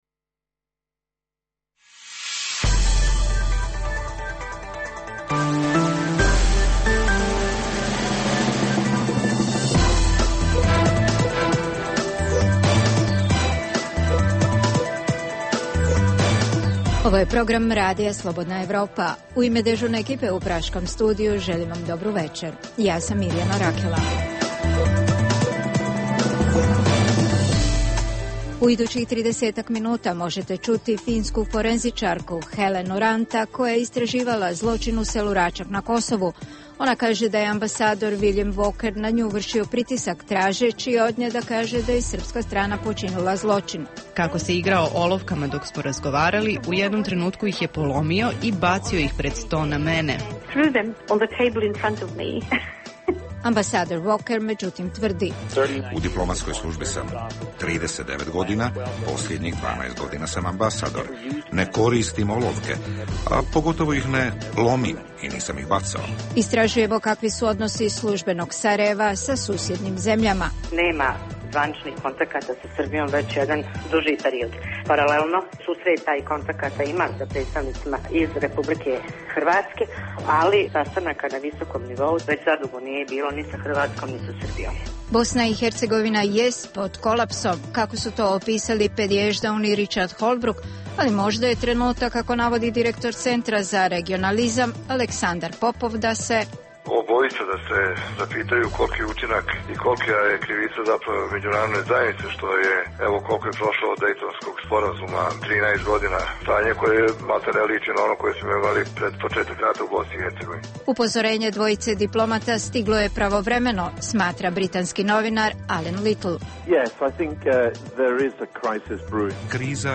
razgovor